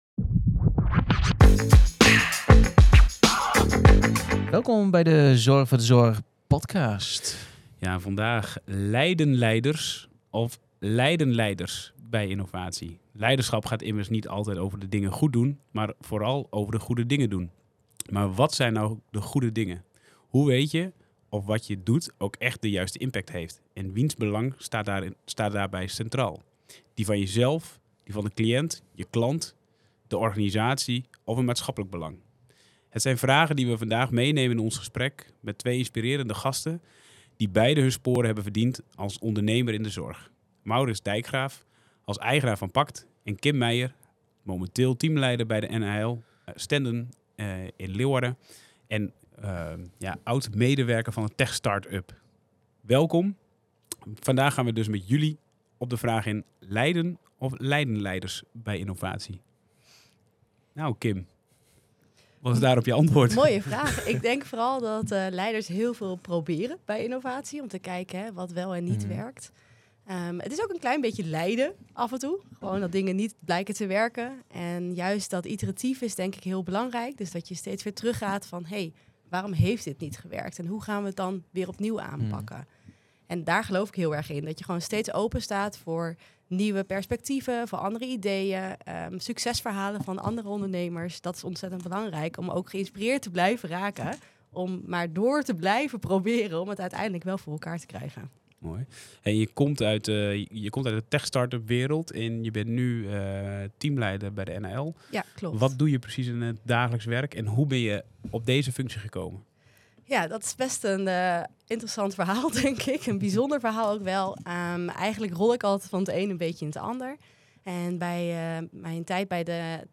Een verdiepend gesprek over lef, visie en de keuzes die technologie en leiderschap met zich meebrengen.